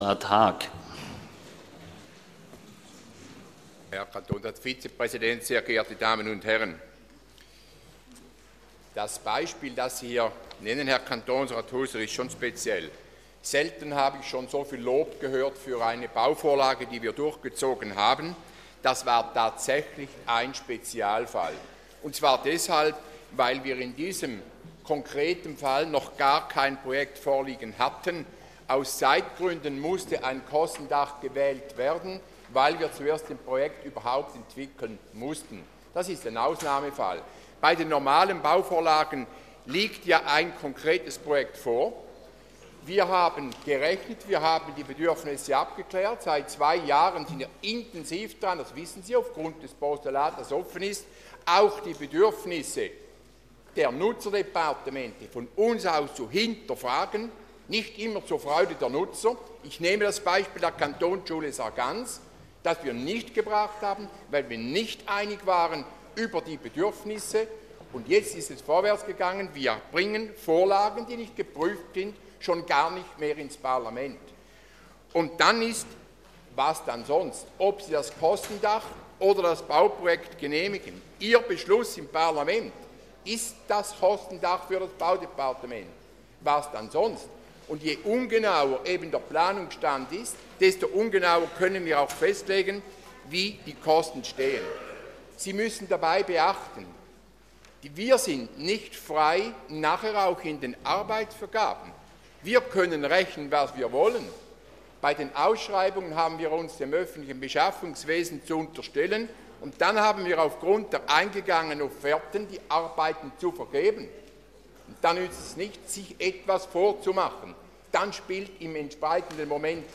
26.2.2013Wortmeldung
Regierungsrat: Auf die Motion ist nicht einzutreten.
Session des Kantonsrates vom 25. bis 27. Februar 2013